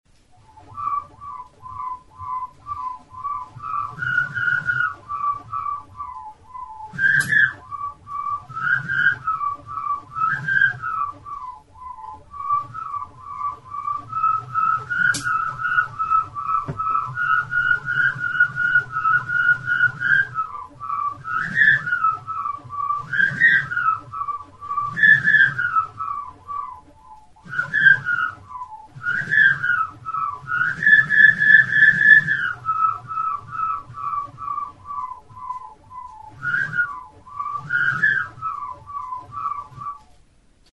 Aerophones
Recorded with this music instrument.
Plastikozko tutu malgu beltza da.